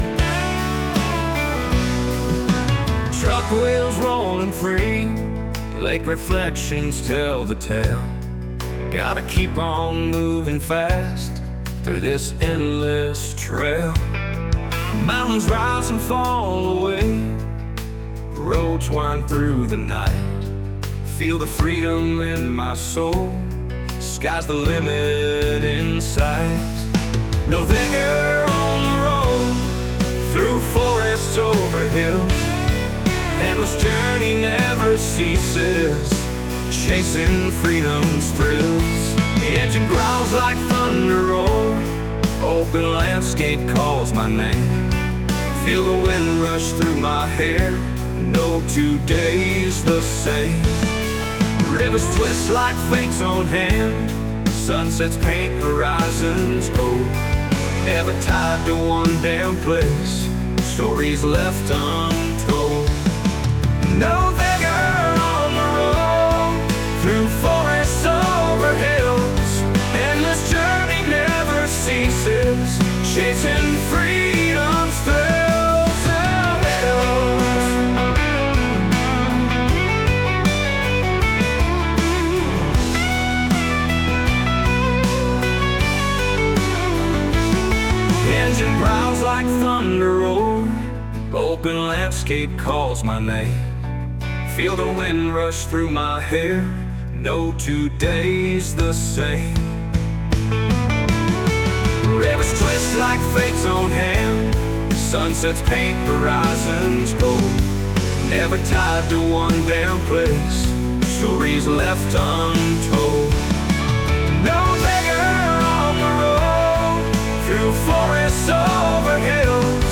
Country-Vibes – unser Nothegger KI-Song
Inspiriert von einer Fahrt entlang des malerischen Pillersees haben wir ein Drohnenvideo aufgenommen und mit einem eigens generierten Country-Song hinterlegt – inklusive eingängigem Refrain, bei dem unser Firmenname nicht fehlen darf.
Der Song wurde mithilfe von Suno, einer KI-gestützten Musikplattform, im typischen Country-Stil erstellt – passend zum Alltag auf Achse.